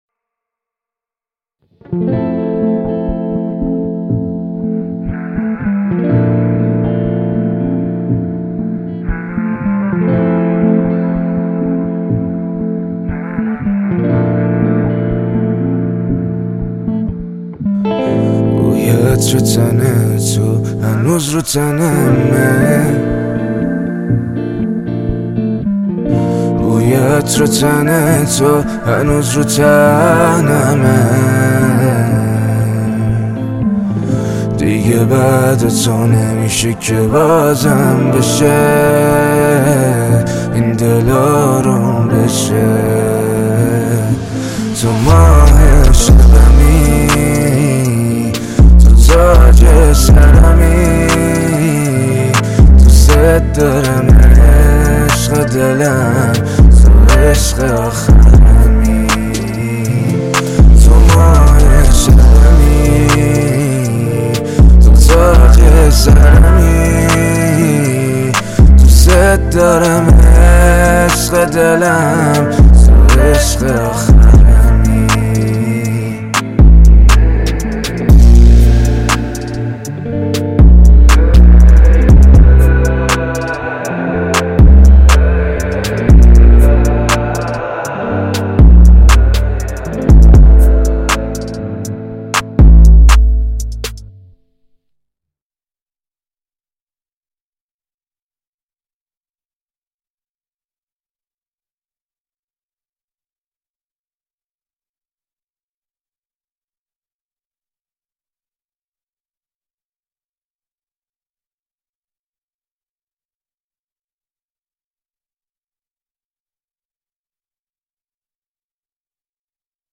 ریمیکس بیس دار سیستمی